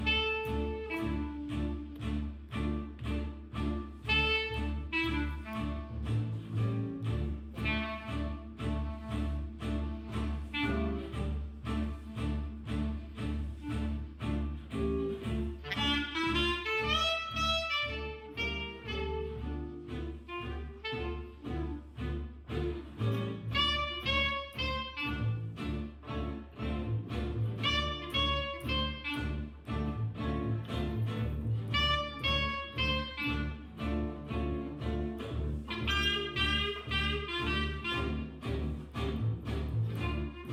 guitare
clarinette
contrebasse